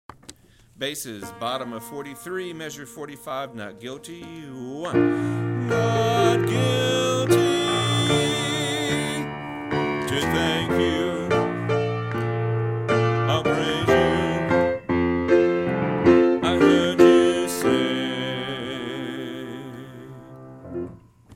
Not Guilty individual voice parts